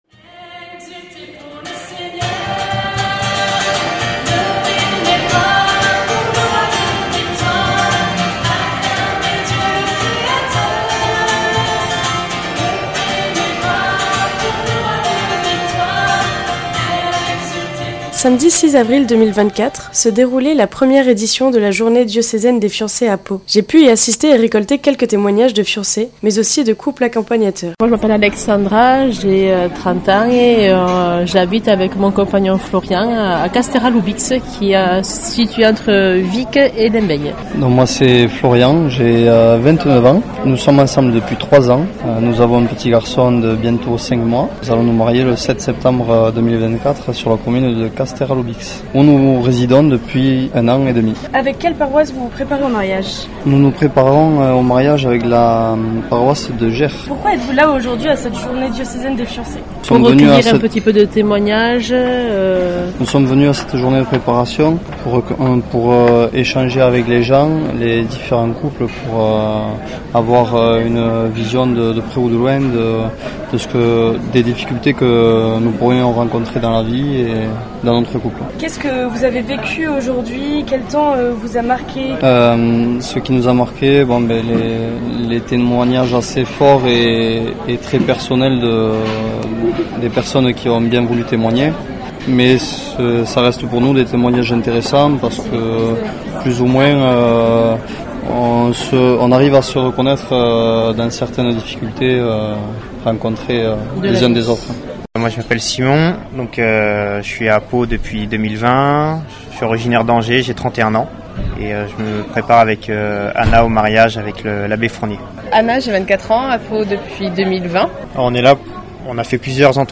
Reportage réalisé à Pau début avril lors de la journée diocésaine des fiancés